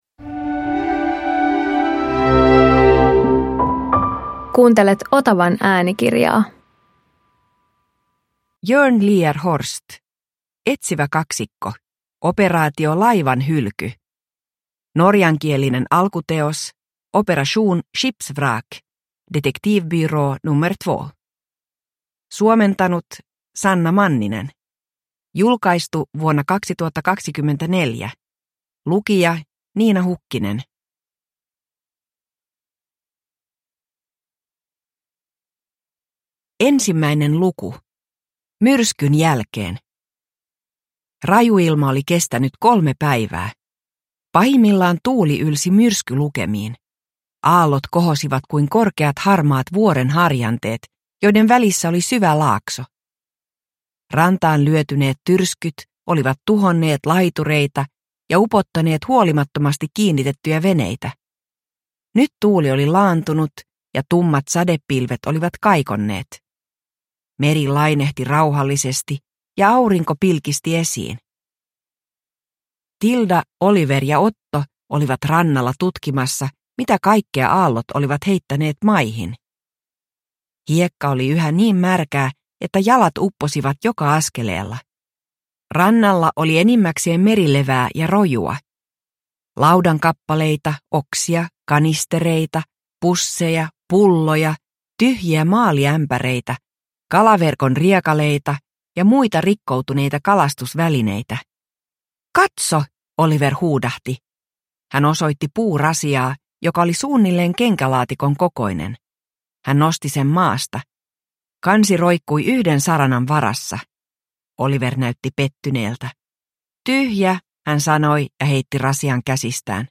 Operaatio Laivanhylky – Ljudbok